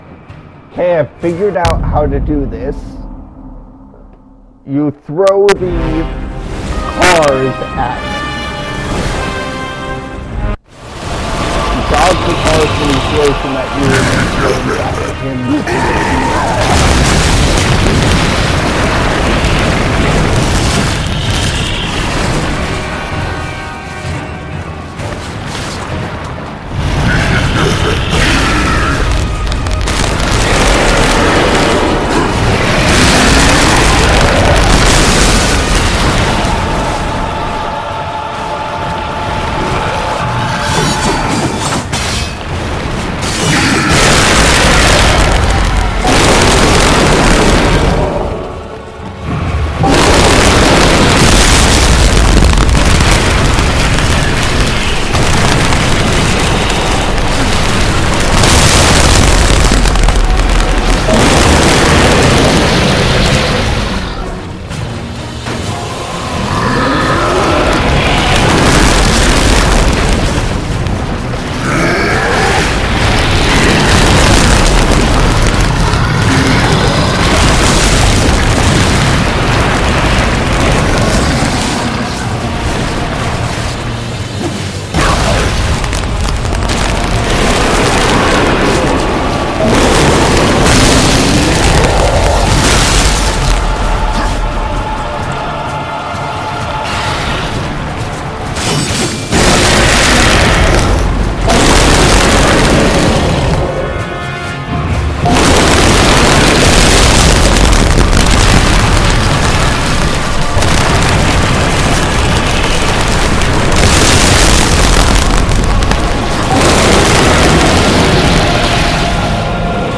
I play Darksiders with commentary